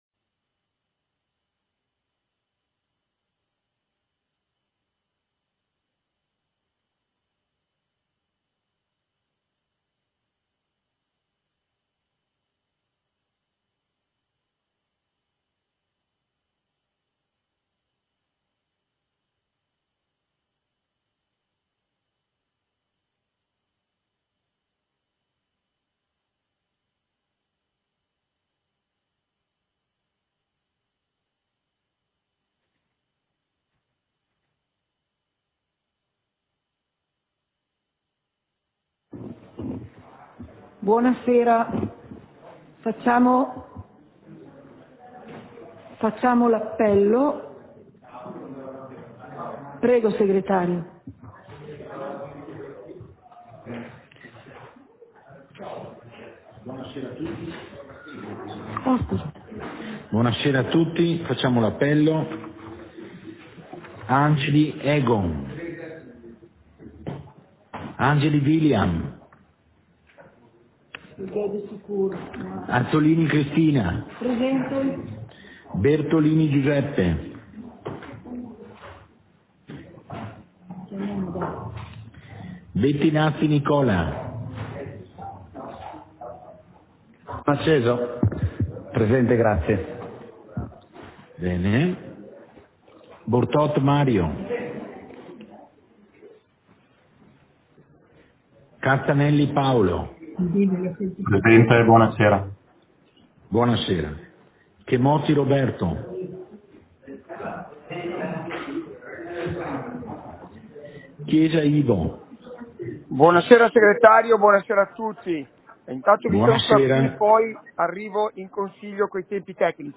Seduta del consiglio comunale - 17.05.2022